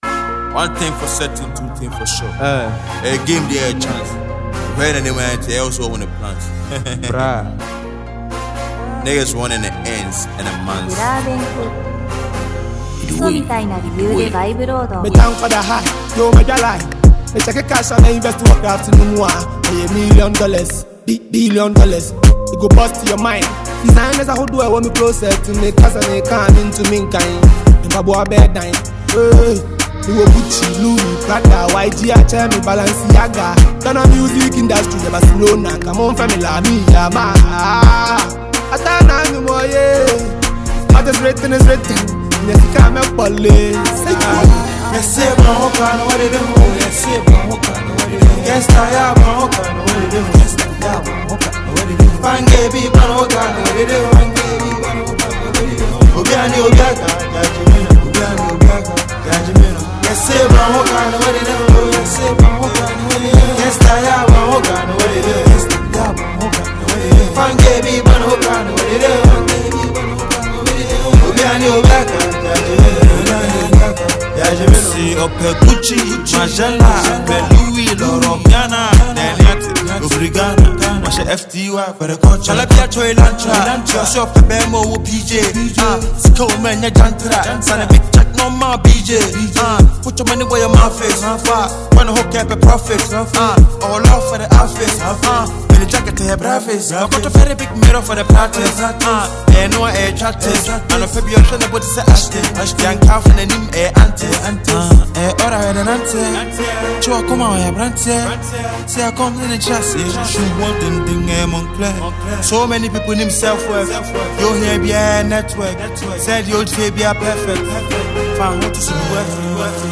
energetic and street-inspired Ghanaian hip-hop record
Genre: Hip-Hop / Drill